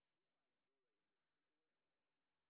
sp07_street_snr0.wav